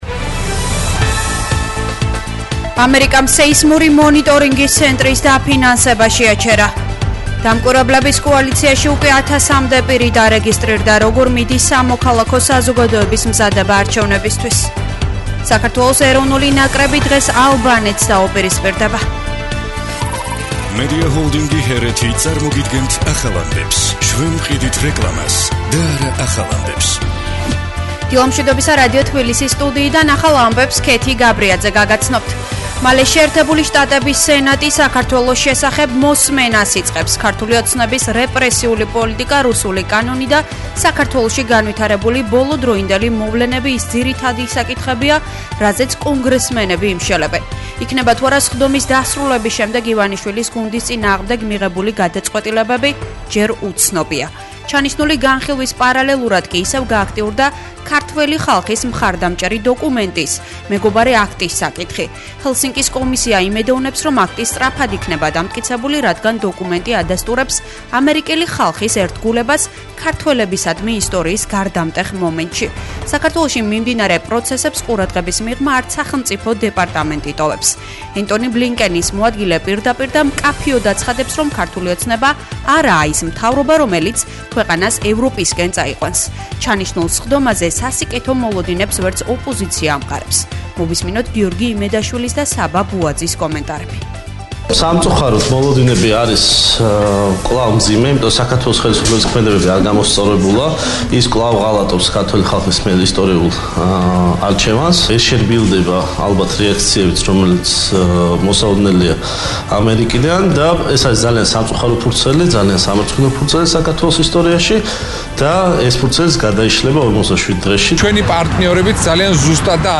ახალი ამბები 09:00 საათზე